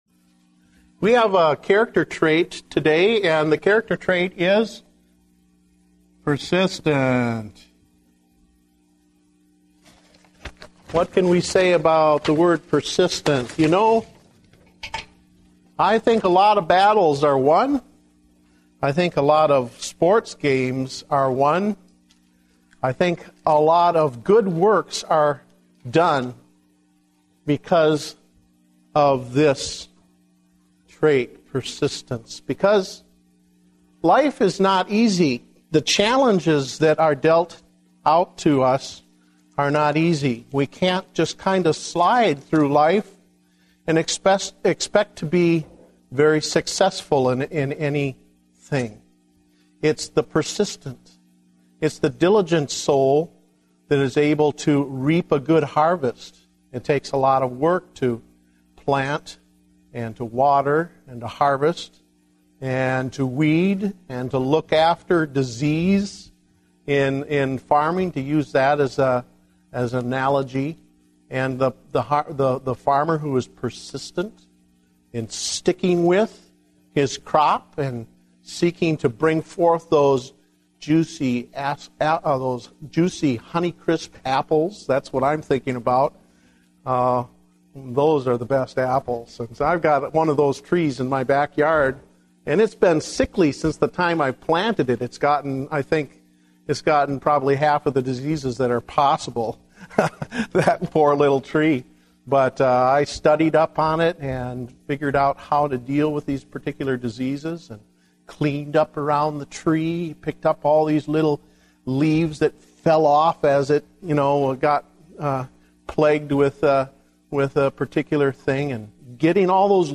Date: February 21, 2010 (Adult Sunday School)